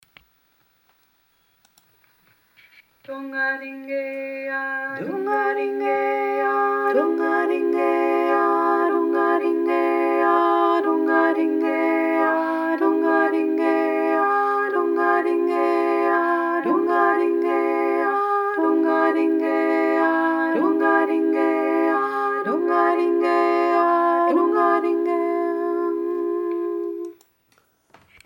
Das sagt jetzt vielleicht einigen nicht viel, aber hört selbst, da ändert sich eine kleine Note und schon klingts ganz anders, zuerst Dur und dann Moll:
Durdreiklang und Molldreiklang
dur-moll.mp3